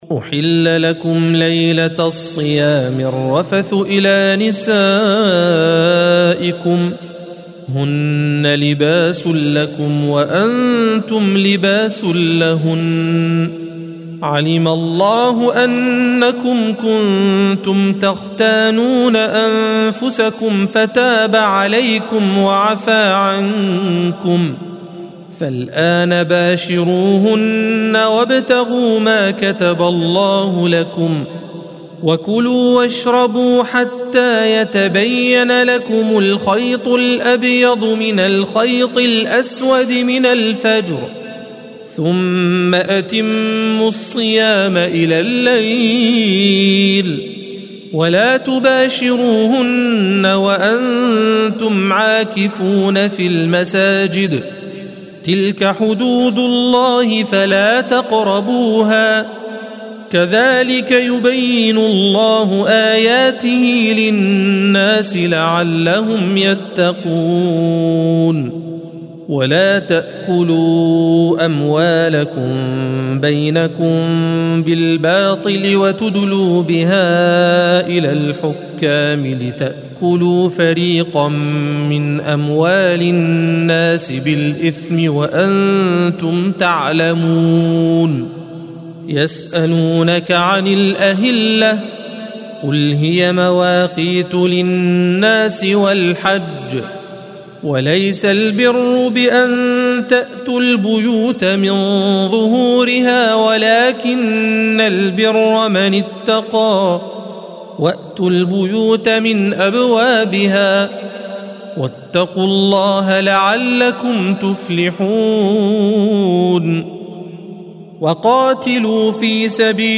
الصفحة 29 - القارئ